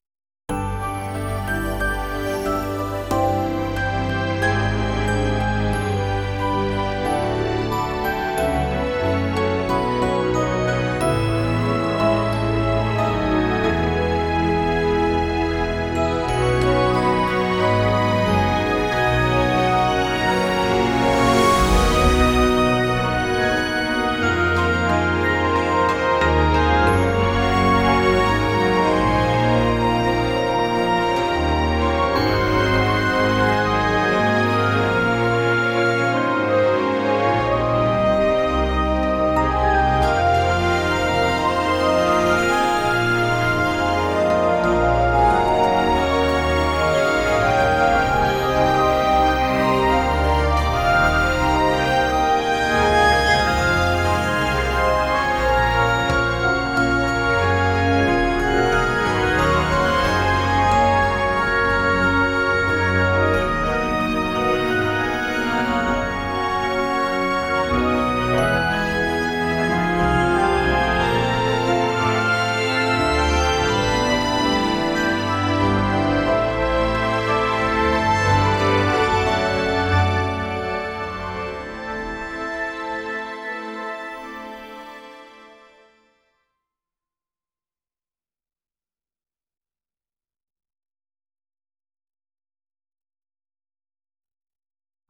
music
TownTheme_5.wav